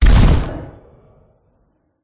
На этой странице собраны звуки, ассоциирующиеся с черной магией: загадочные шёпоты, ритуальные напевы, эхо древних заклинаний.
Звук магического выстрела